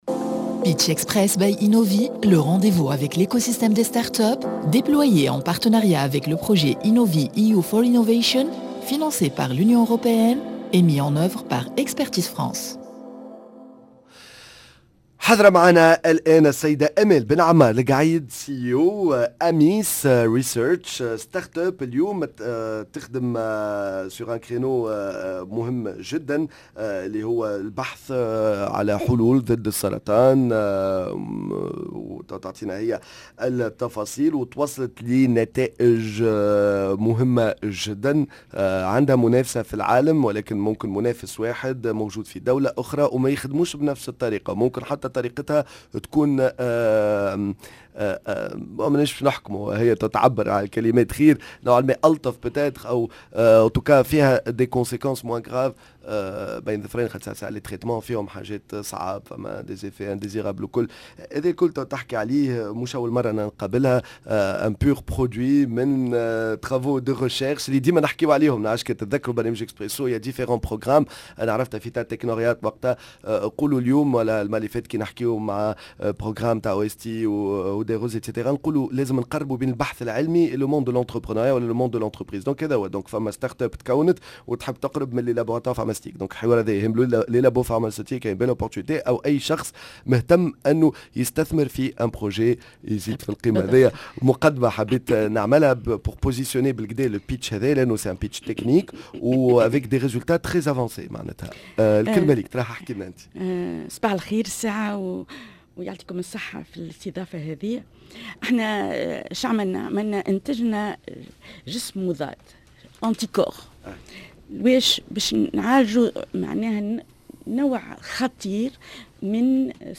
pitch